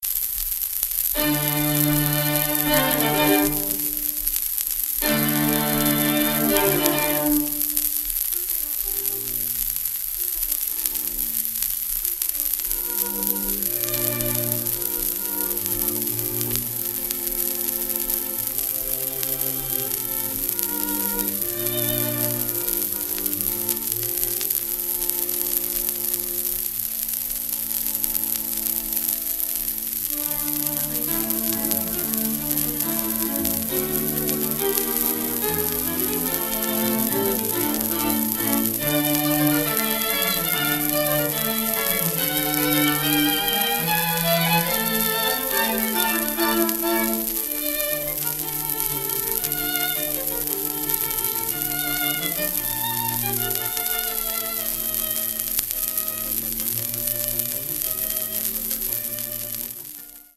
1936年10月16日ロンドン録音)